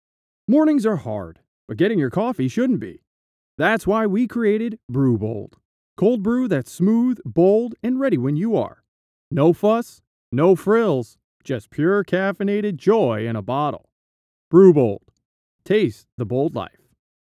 Male
Yng Adult (18-29), Adult (30-50)
Radio Commercials
Coffee Commercial